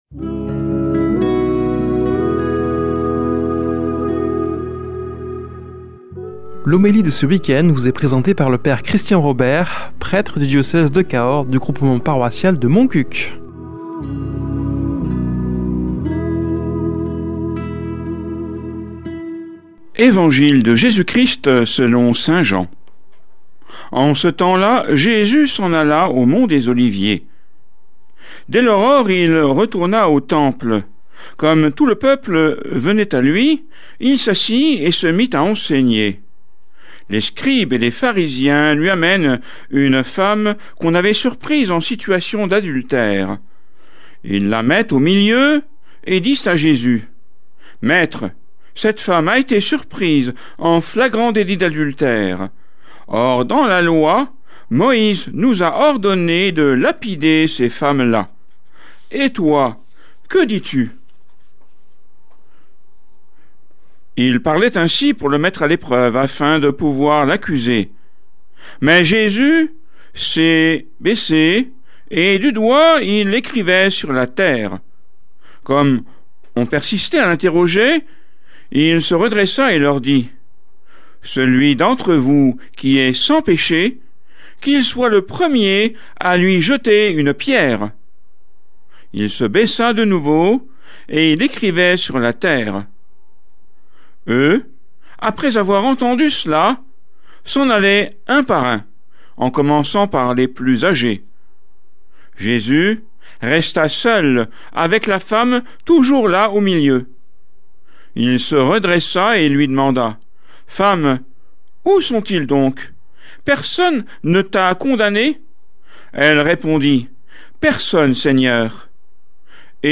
Homélie du 05 avr.